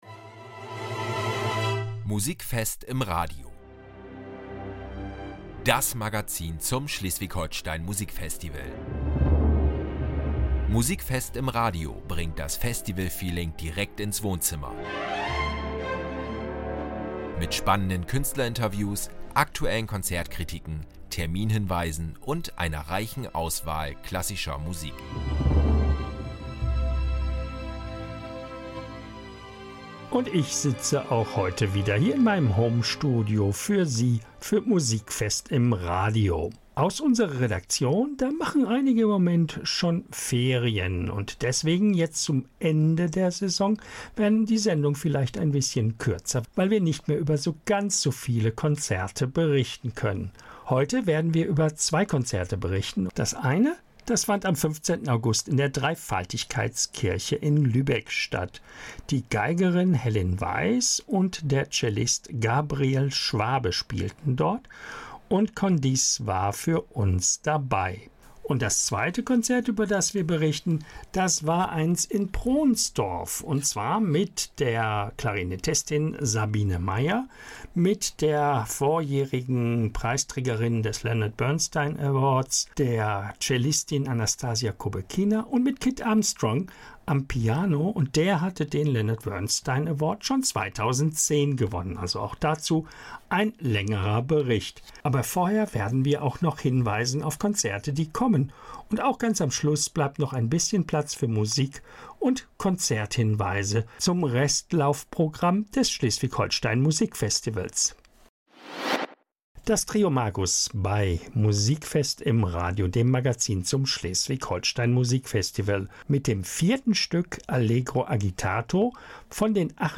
In der achten Episode von Musikfest im Radio sprechen wir mit Max Mutzke über sein Konzert mit dem Takeover Ensemble: eine Symbiose aus Stimme, Rhythmus und ensemblebetonter Kreativität. Mit Herzblut und feinem Gespür beleuchtet er das Zusammenspiel von Soul, Klassik und innovativen Arrangements – und erzählt, was ihn an diesem Format besonders fasziniert.